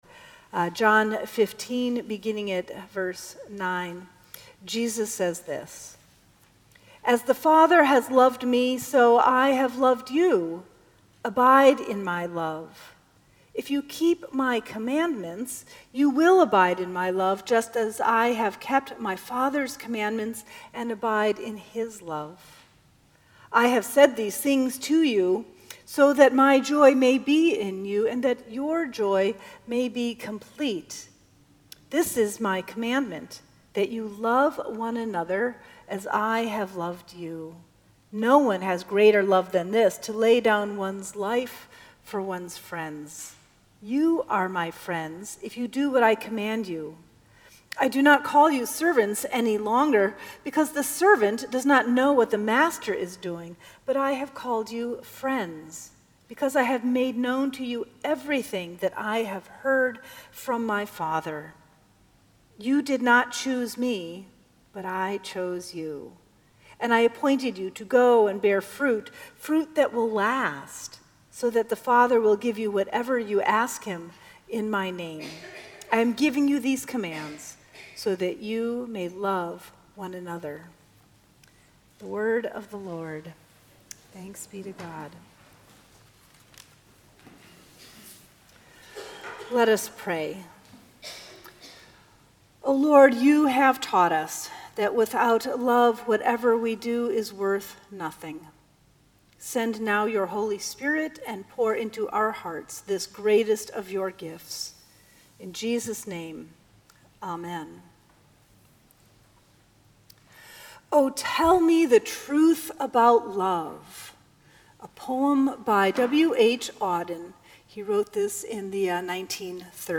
Sermons at Union Congregational Church
May 6, 2018 Sixth Sunday of Easter